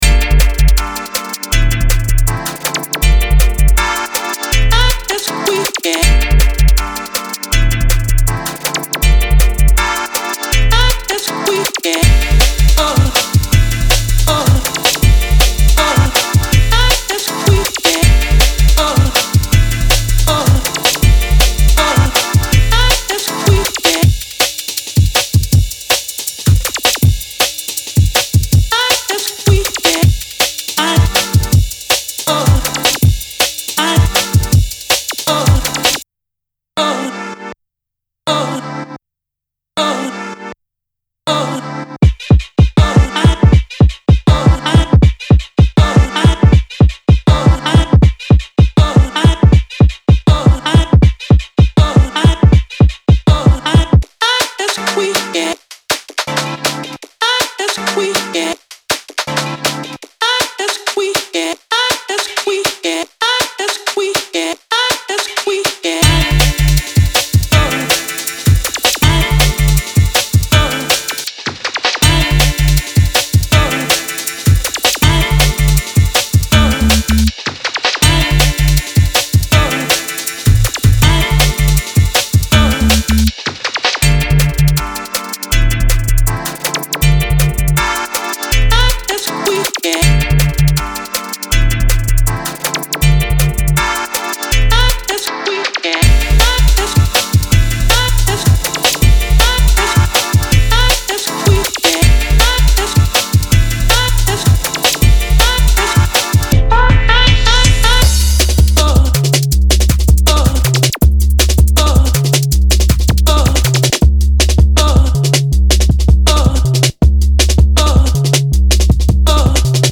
refreshingly optimistic bit of juke